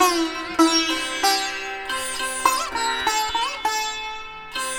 100-SITAR6-R.wav